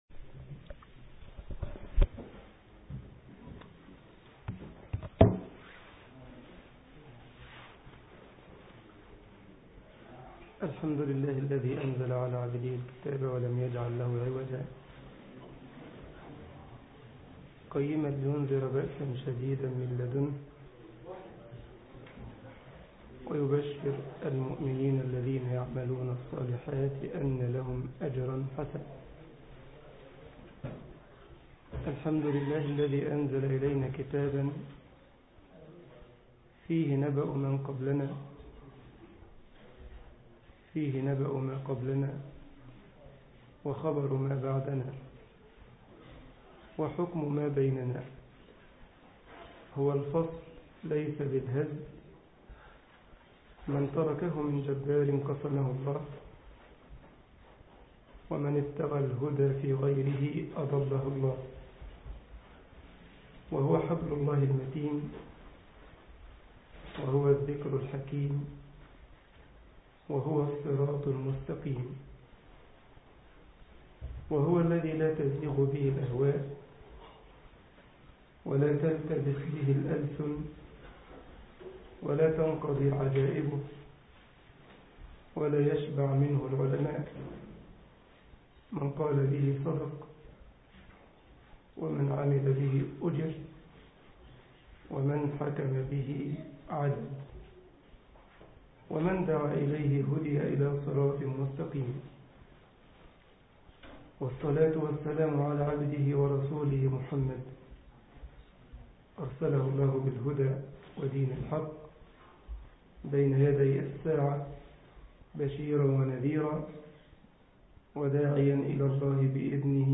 مسجد كايزرسلاوترن ـ ألمانيا محاضرة